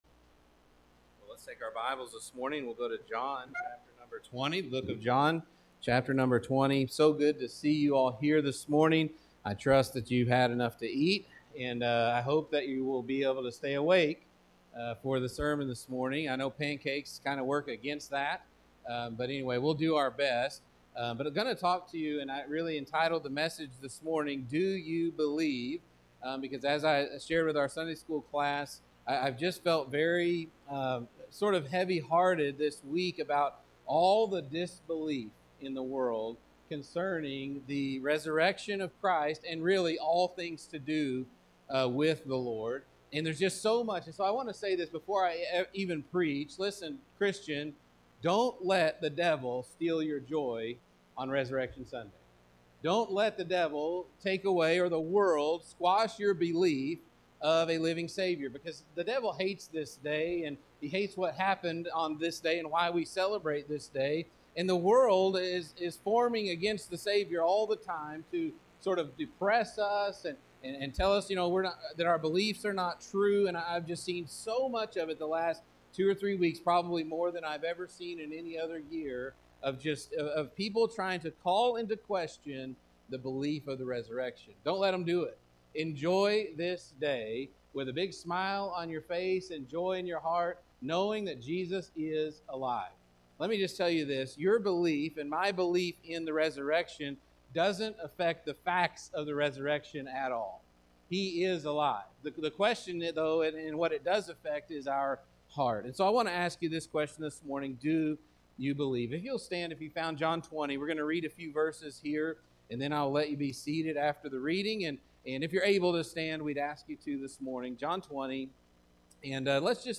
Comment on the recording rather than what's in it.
Easter-Sunday-Do-you-believe.mp3